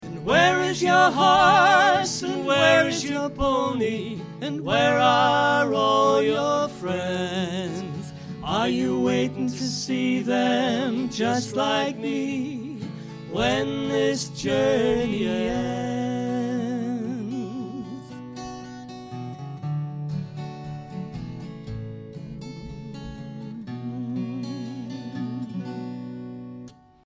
Heavily influenced by bluegrass and American folk they go for a strong melody, good basic chord structure and harmony singing.
double bass
guitar and harmonica